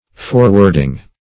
Forwarding - definition of Forwarding - synonyms, pronunciation, spelling from Free Dictionary
Forwarding \For"ward*ing\, n.